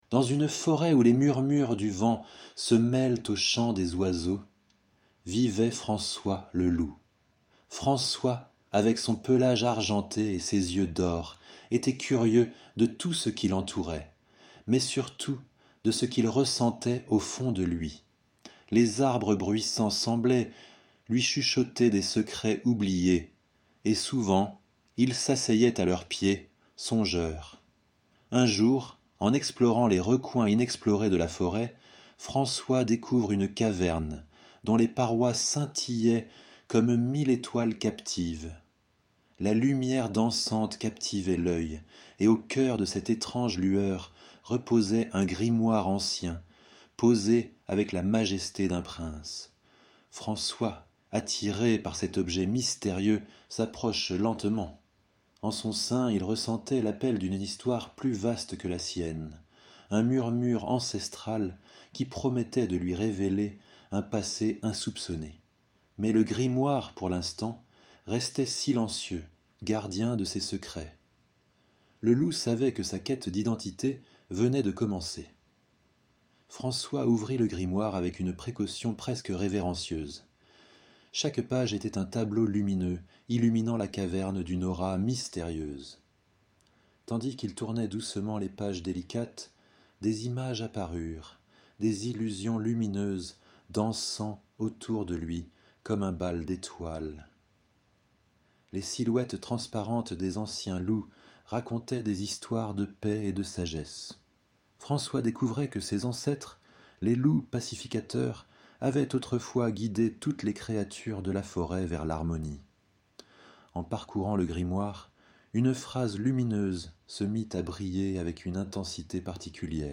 🎧 Lecture audio générée par IA
Conte : François le Loup Éclaireur